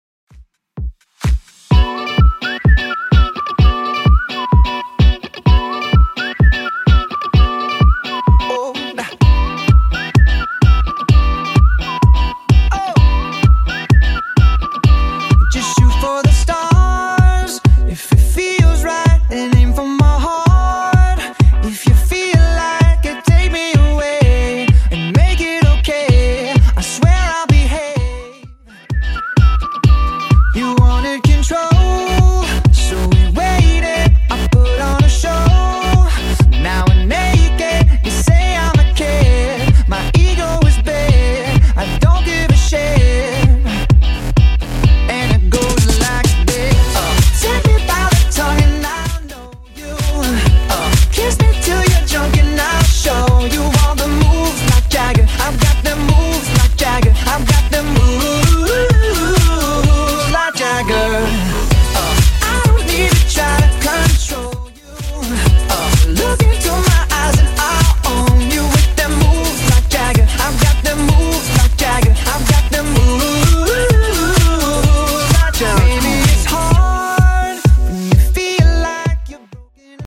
Genre: 80's
BPM: 125